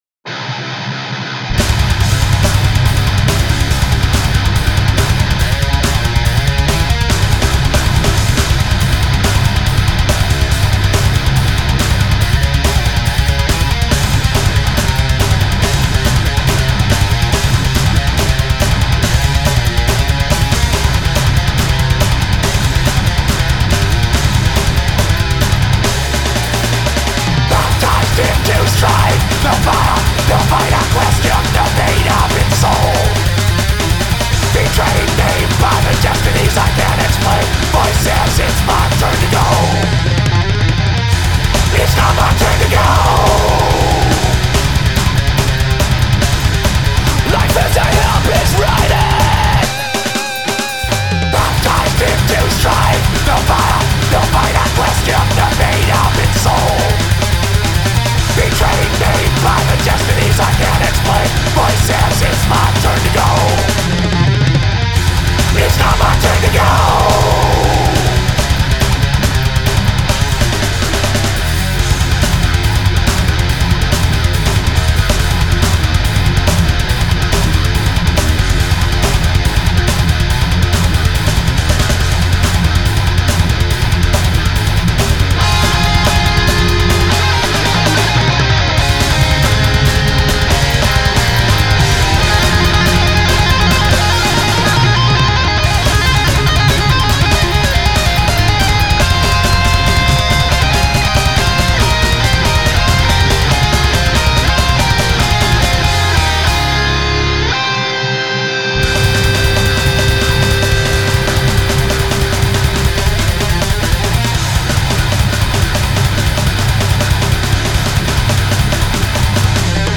Guitar/Vox
Bass/Vox
Drums
Guitars